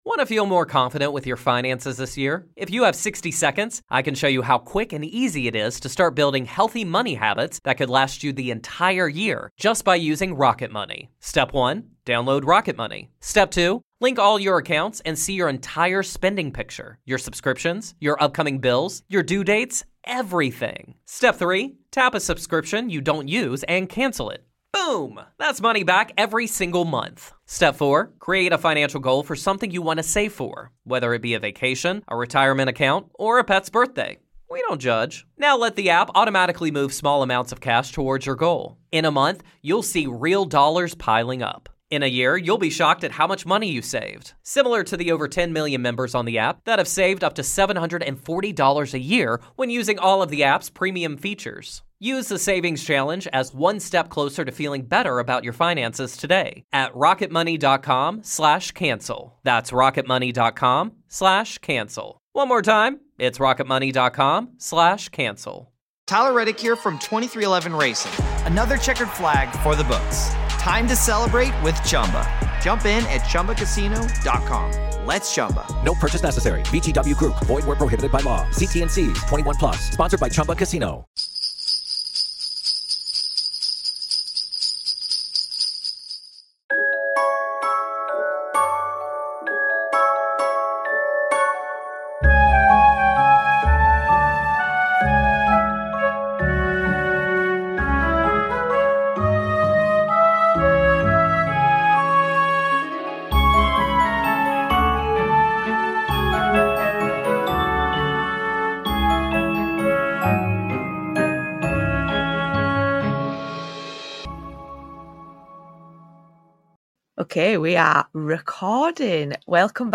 A easy breezy conversational episode talking Yuletide history and plans between two witchy friends.